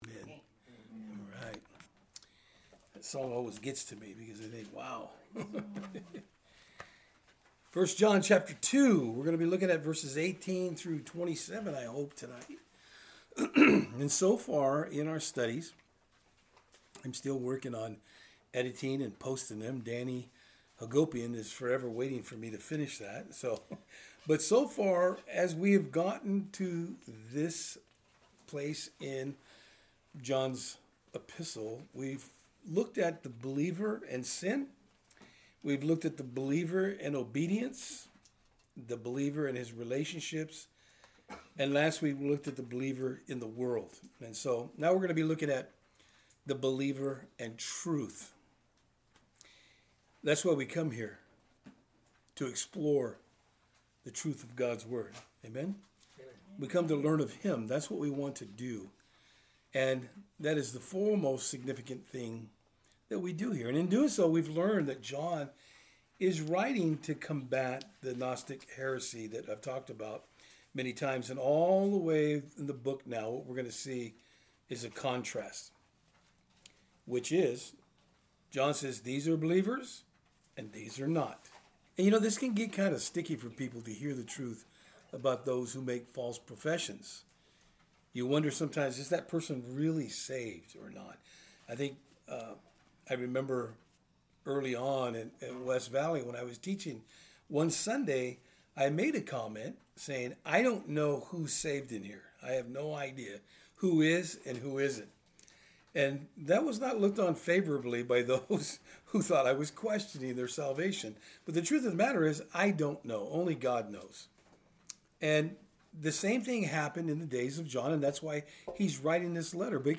Service Type: Thursday Eveing Studies